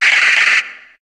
Cri de Branette dans Pokémon HOME.